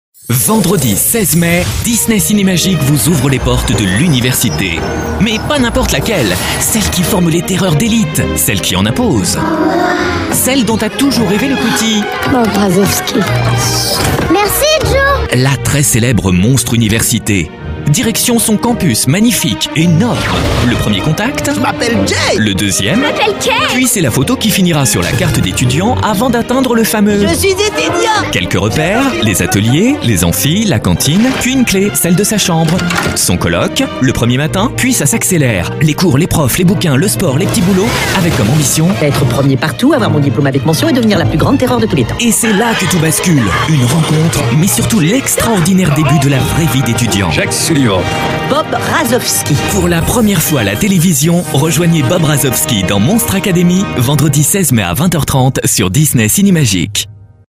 Genre : voix off.